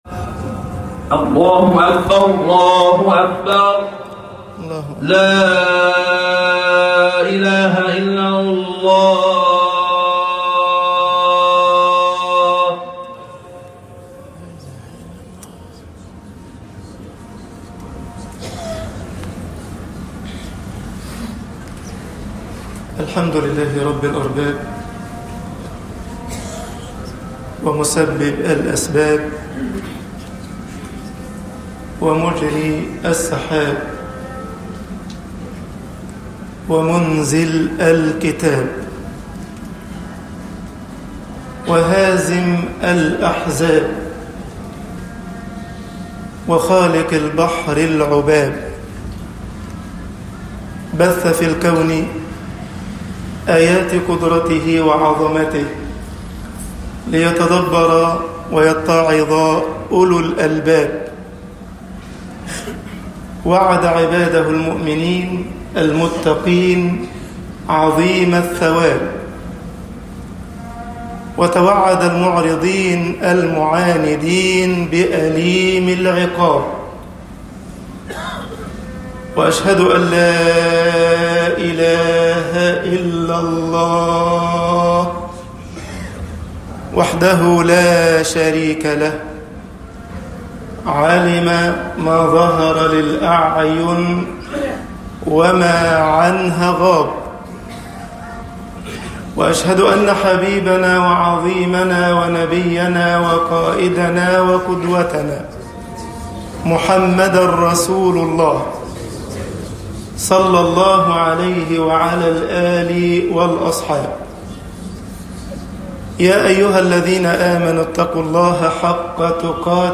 خطب الجمعة - مصر آيَةُ الْإعْدَادِ للأعْدَاءِ